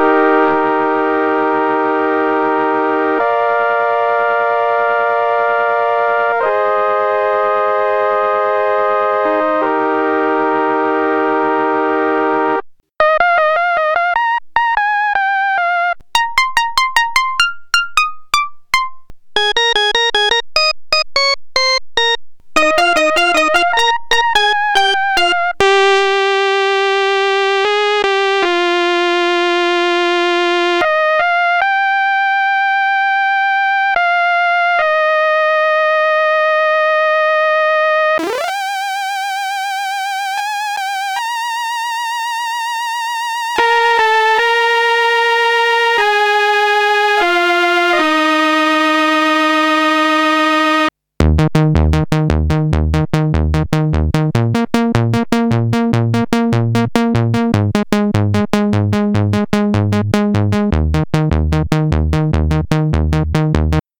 Das sind Musikinstrumente, die auf elektronischem Wege Klänge erzeugen.
Bild 8.41: Synthesizer von 1979 (Hörprobe:
MicroKORG_Demo.ogg